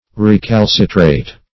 Recalcitrate \Re*cal"ci*trate\ (r[-e]*k[a^]l"s[i^]*tr[=a]t), v.